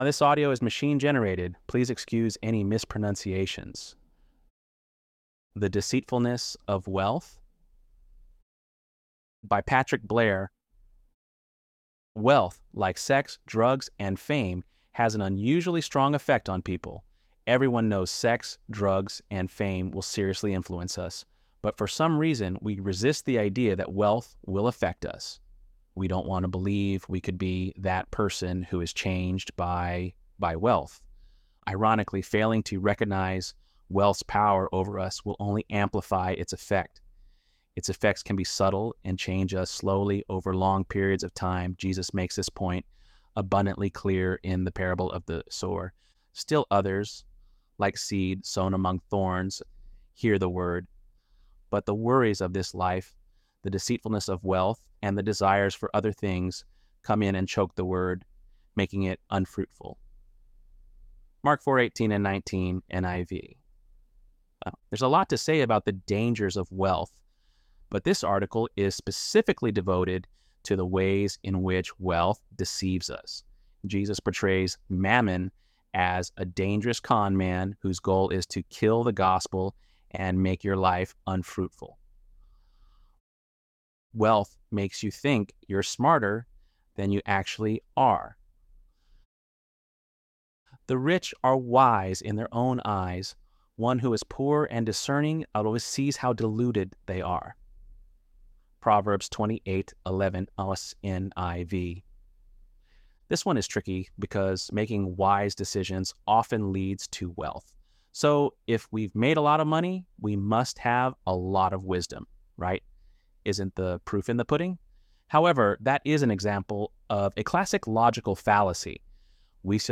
ElevenLabs_11_13.mp3